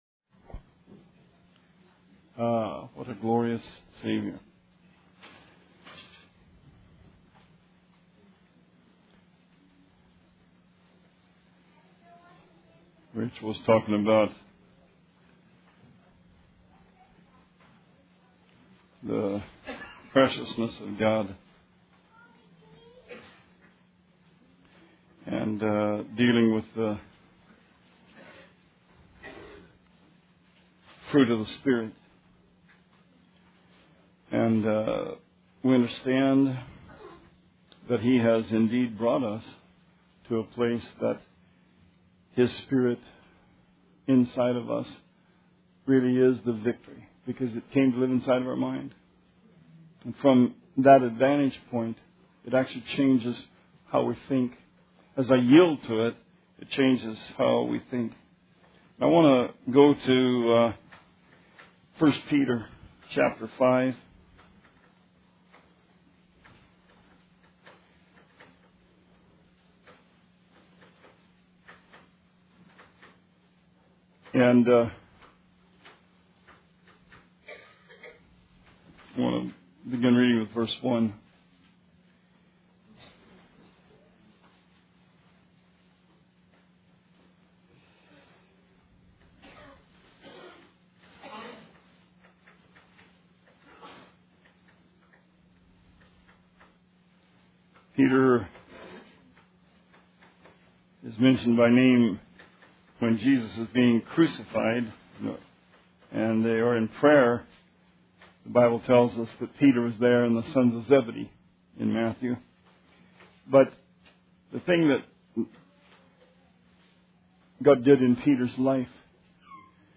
Sermon 4/16/17